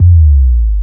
BASS61  02-L.wav